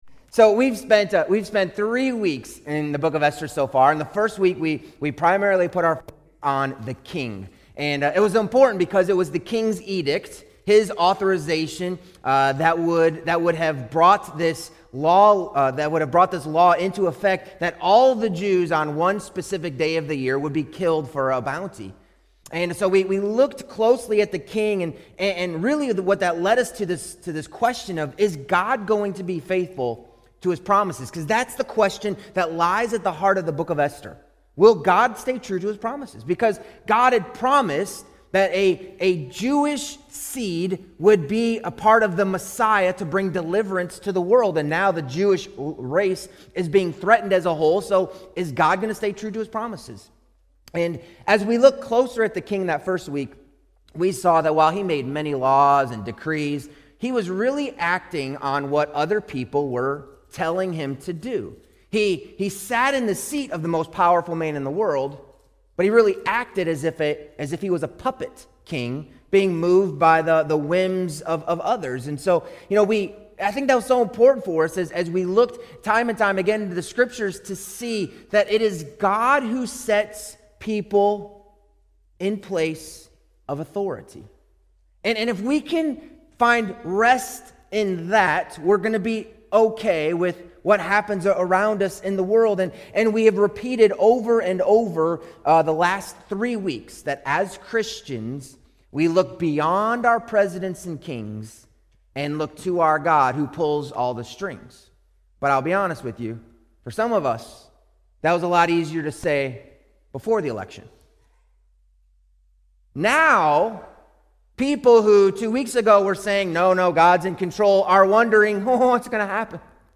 Sermons | Mt. Carmel Regular Baptist Church